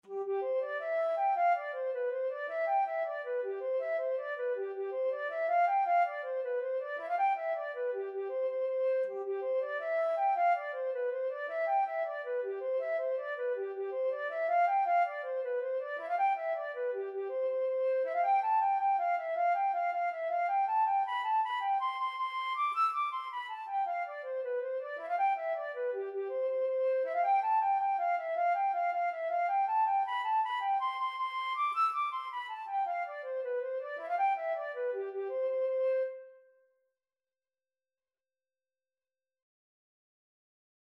Flute version
C major (Sounding Pitch) (View more C major Music for Flute )
6/8 (View more 6/8 Music)
Flute  (View more Intermediate Flute Music)
Traditional (View more Traditional Flute Music)